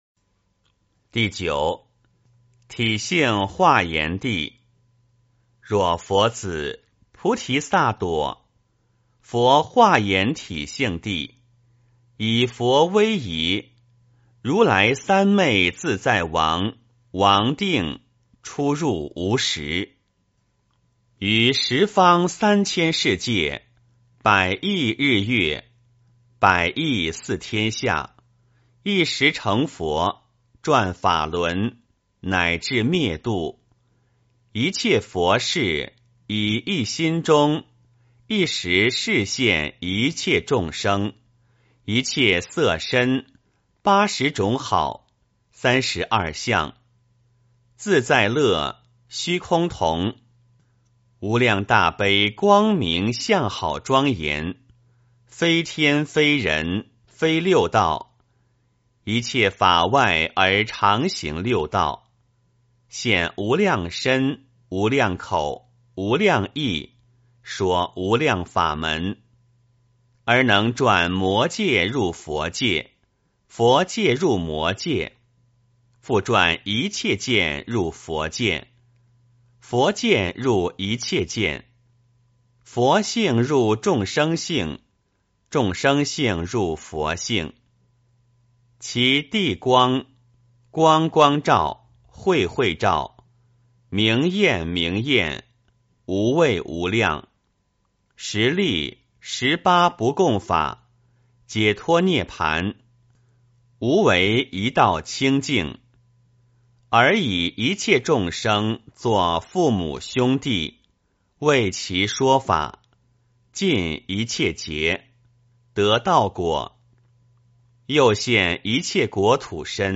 梵网经-十地-体性华严地 - 诵经 - 云佛论坛